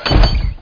1 channel
doorcls3.mp3